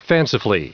Prononciation du mot fancifully en anglais (fichier audio)
Prononciation du mot : fancifully
fancifully.wav